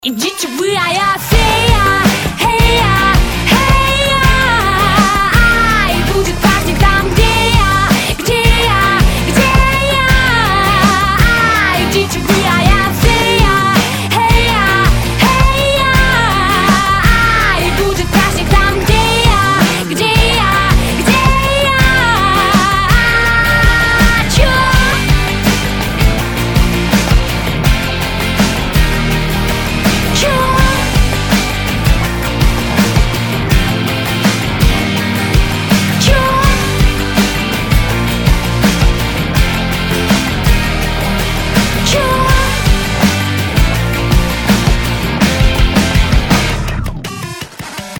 • Качество: 320, Stereo
веселые
смешные
дерзкие
Отбивка команды КВН